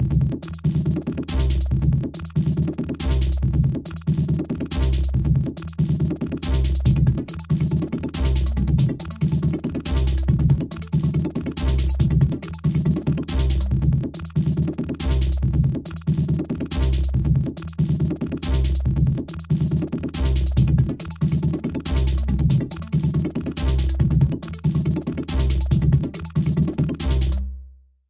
描述：鼓节奏样本序列击鼓敲击心律失常节奏
Tag: 韵律 打击乐器 样品 心律失常 节拍 序列 有节奏